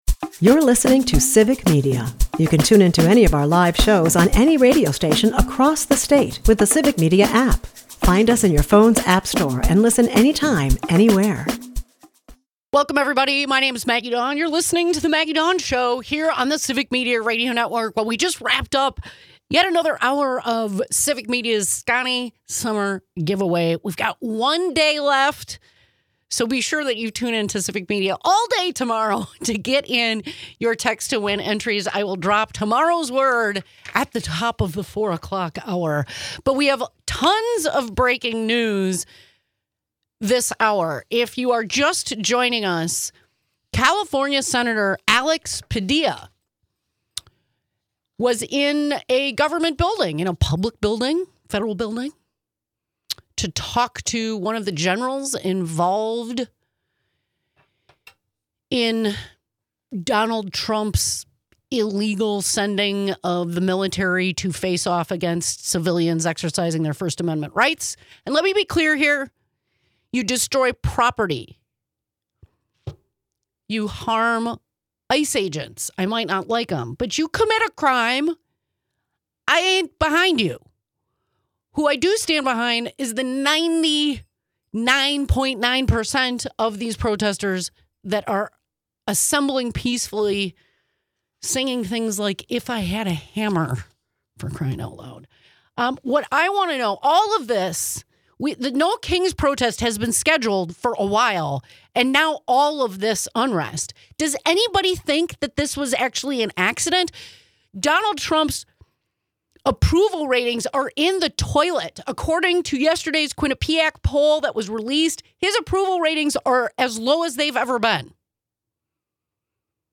Callers from across Wisconsin weigh in—some voicing fear over immigration crackdowns, others defiantly committed to protest and organizing.